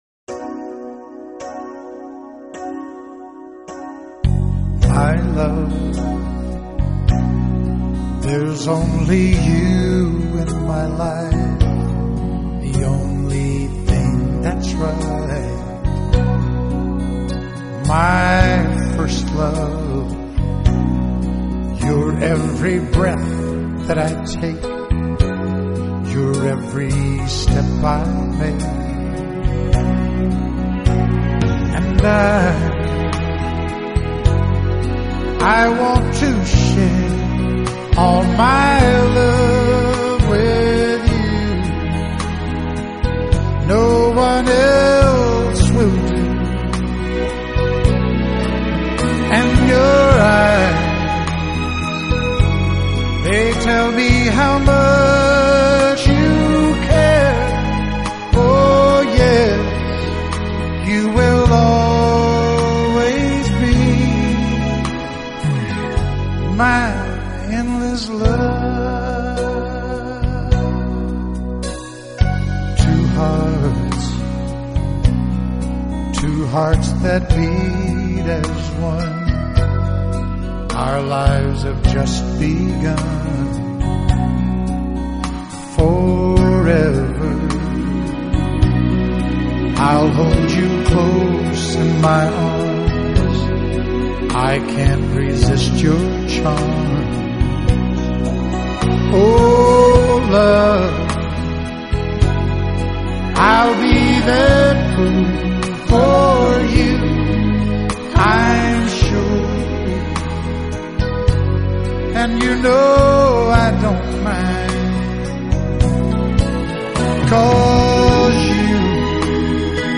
乡村歌曲